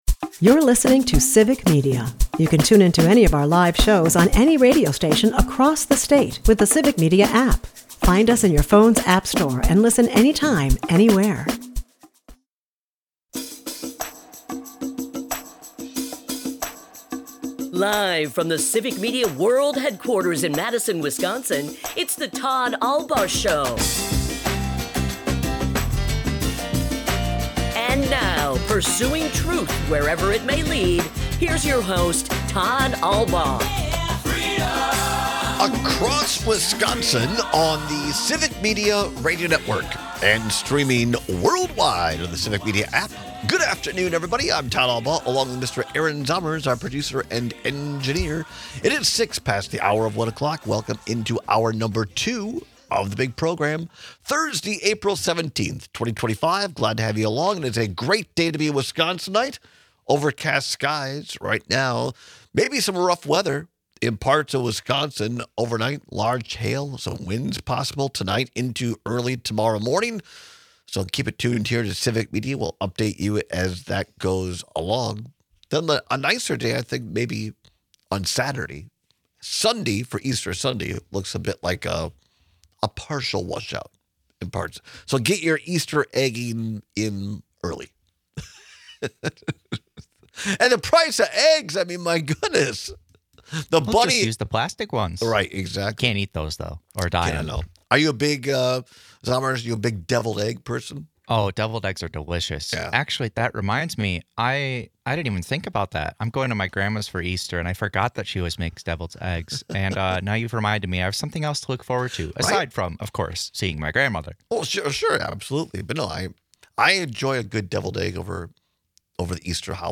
We ask listeners to call and text in, but it’s no big deal.